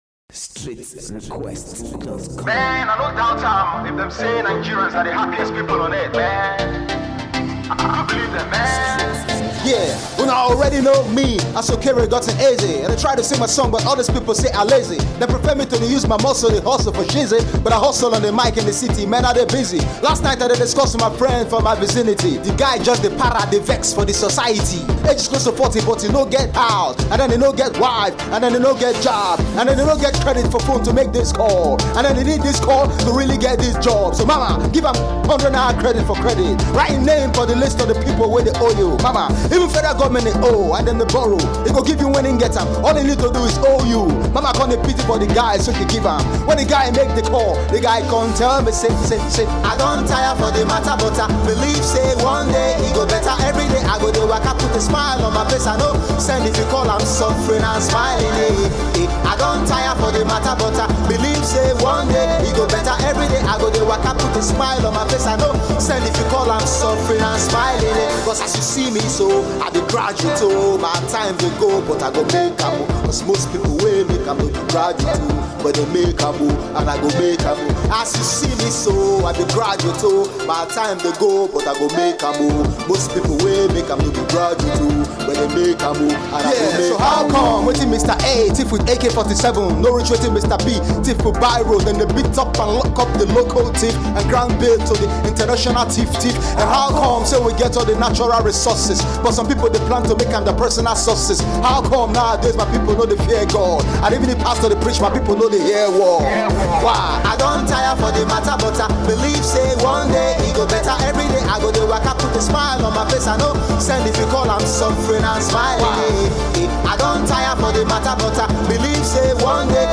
Lagos-based rapper